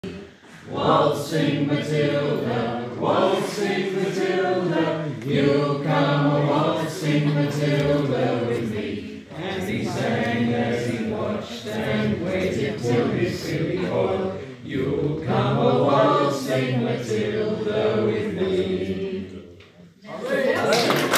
délégation australienne chant
delegation_australienne_chant.m4a